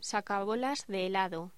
Locución: Sacabolas de helado
locución
Sonidos: Voz humana